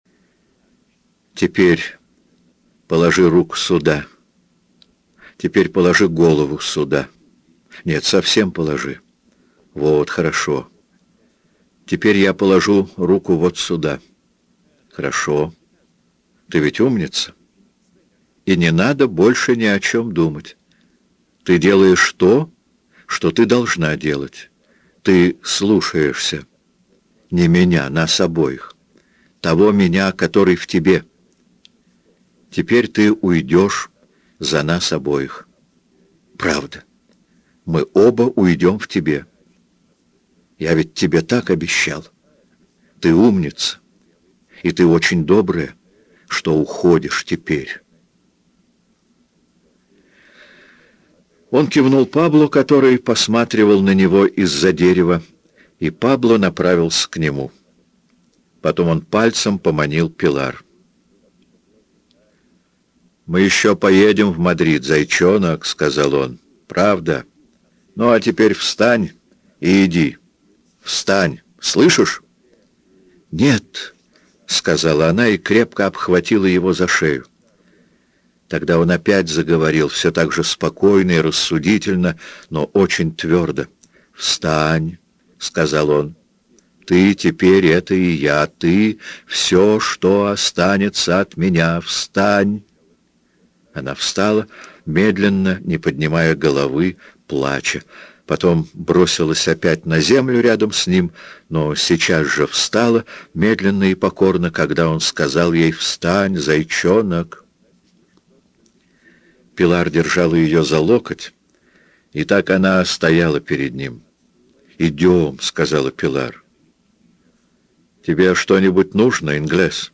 Аудиокнига По ком звонит колокол
Качество озвучивания весьма высокое.